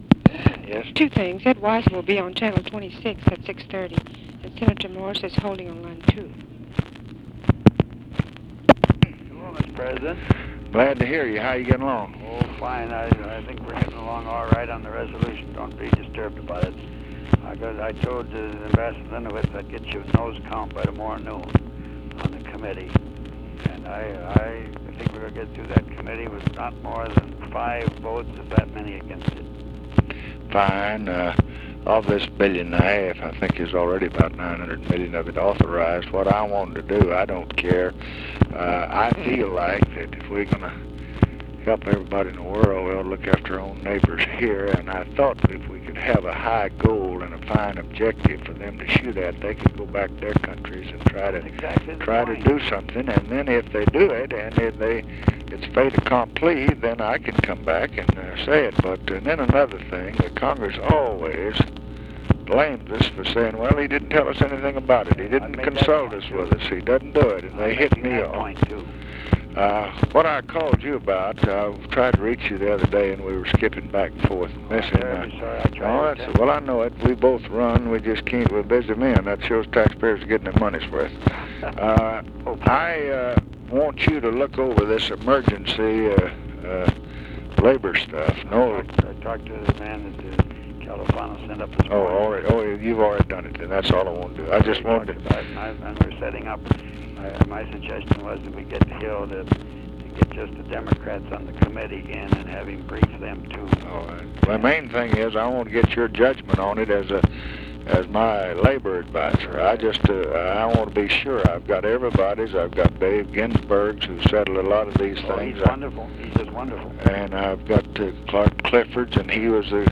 Conversation with WAYNE MORSE and OFFICE SECRETARY, March 14, 1967
Secret White House Tapes